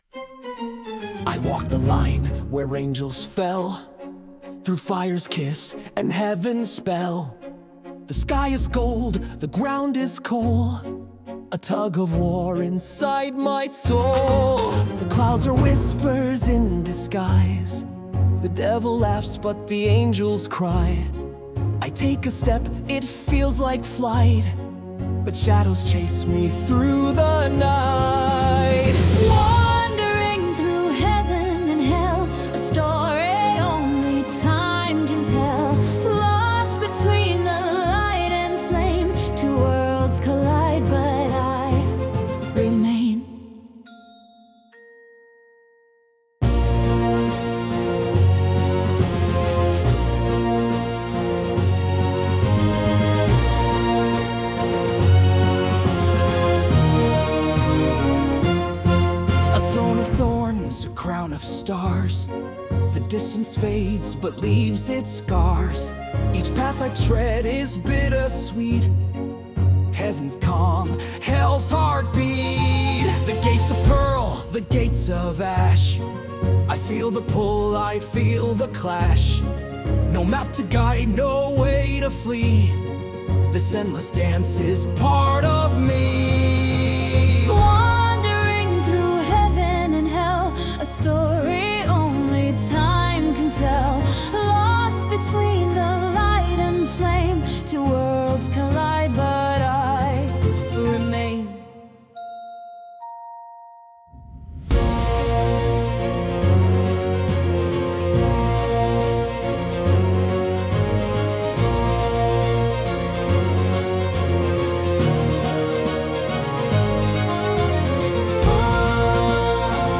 🌍 Emotional, dramatic, and deeply expressive.
With heartfelt vocals and cinematic dynamics,
心の中で揺れ動く感情を描いた、歌入りのオリジナル新体操ミュージック。
静かな葛藤から力強い決意へ——
ドラマチックに展開していくメロディと歌声が、演技を深く彩ります。